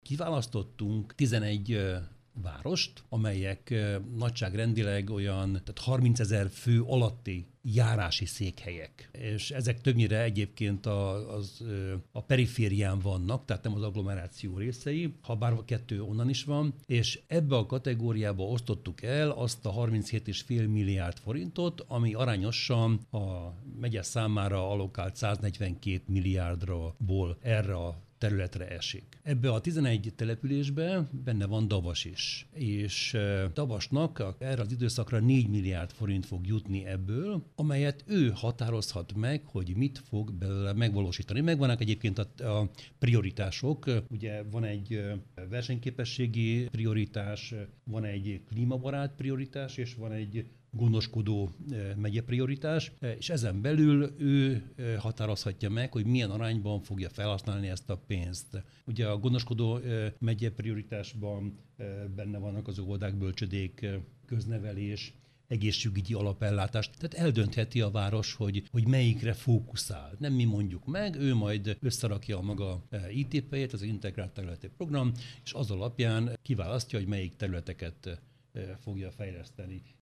Szabó Istvánt, a megyei Közgyűlés elnökét hallják.